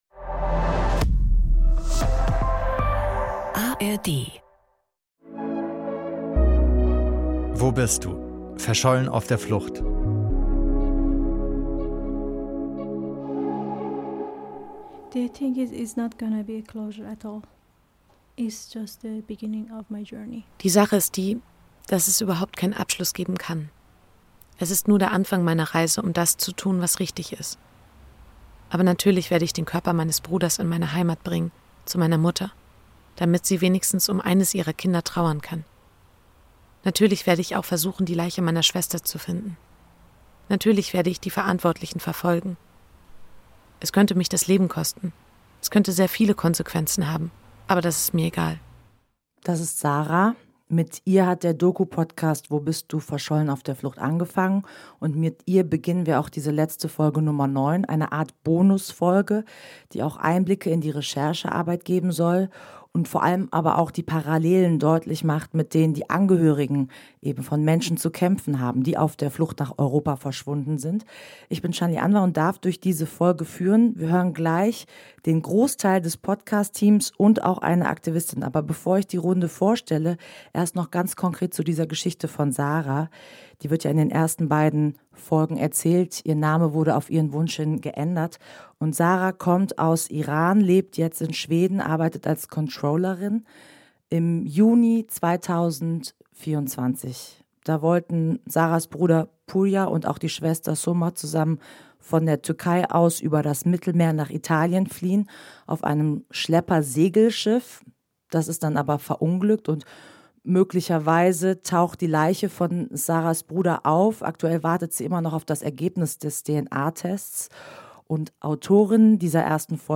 Das Team von investigativen Journalistinnen und Journalisten erzählt von ihrer Arbeit an „Wo bist Du?“.